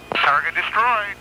AGKill_TARGET_DESTROYED.ogg